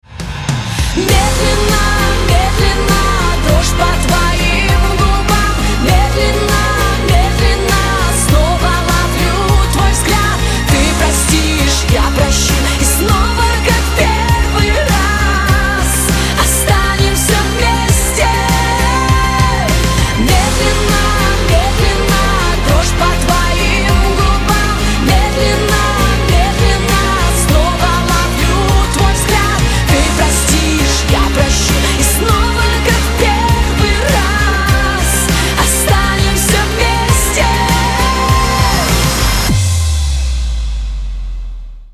• Качество: 256, Stereo
поп
громкие